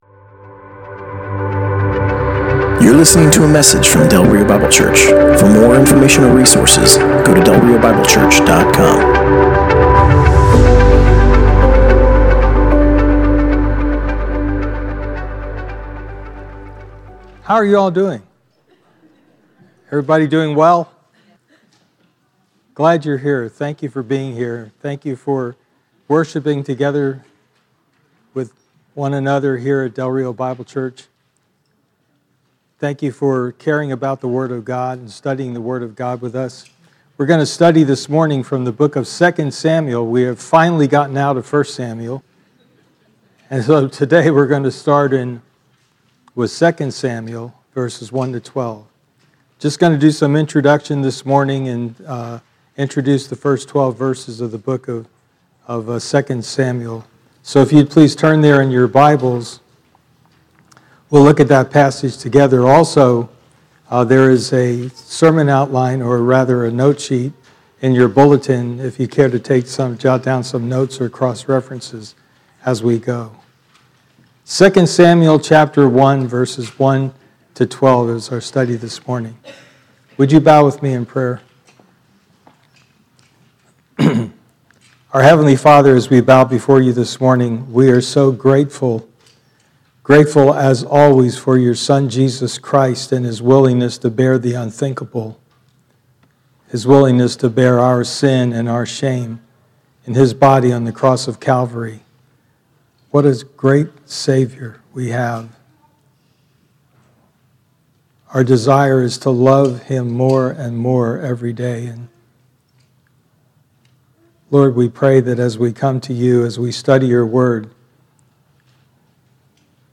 Passage: 2 Samuel 1: 1-12 Service Type: Sunday Morning